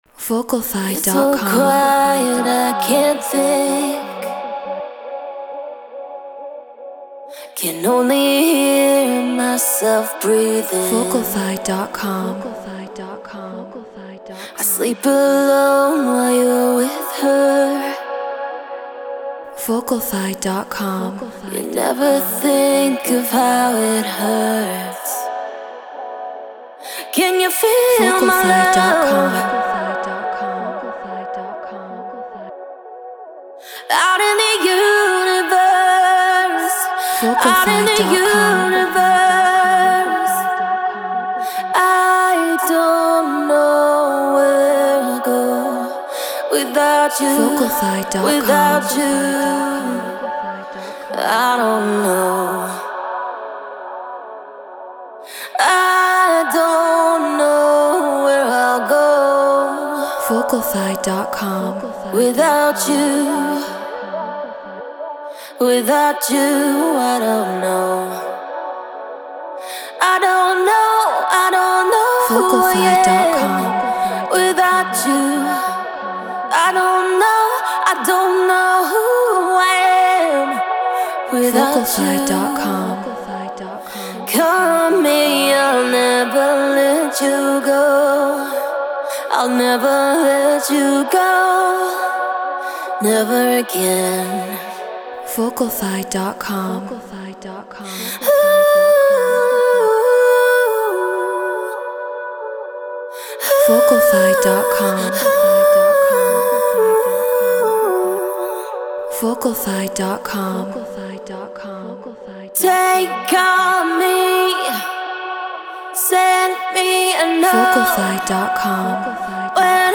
Trance 138 BPM F#min